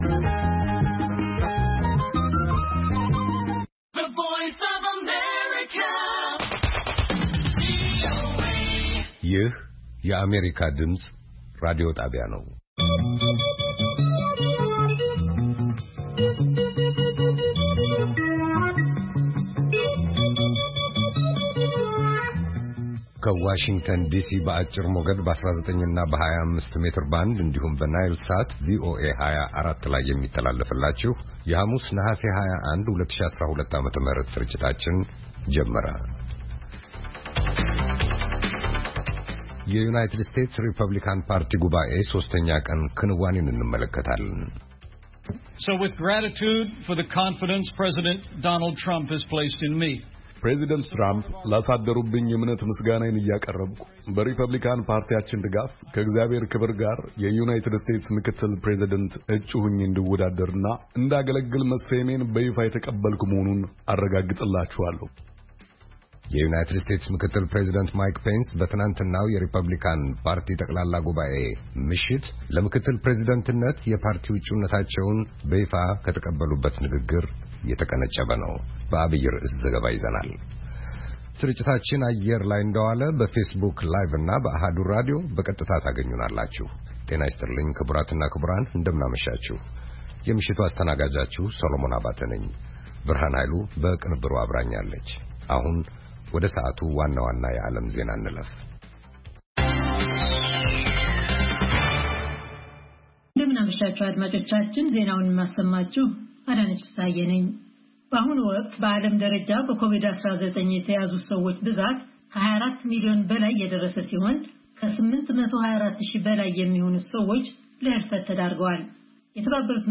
ሐሙስ፡-ከምሽቱ ሦስት ሰዓት የአማርኛ ዜና
ቪኦኤ በየዕለቱ ከምሽቱ 3 ሰዓት በኢትዮጵያ አቆጣጠር ጀምሮ በአማርኛ፣ በአጭር ሞገድ 22፣ 25 እና 31 ሜትር ባንድ የ60 ደቂቃ ሥርጭቱ ዜና፣ አበይት ዜናዎች ትንታኔና ሌሎችም ወቅታዊ መረጃዎችን የያዙ ፕሮግራሞች ያስተላልፋል። ሐሙስ፡- ባሕልና ማኅበረሰብ፣ ሕይወት በቀበሌ፣ የተፈጥሮ አካባቢ፣ ሣይንስና ሕይወት